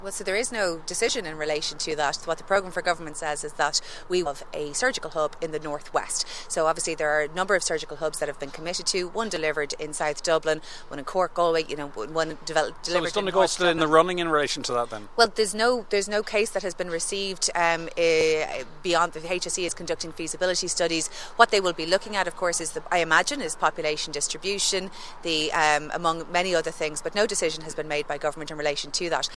outside of the Errigal ECC Hub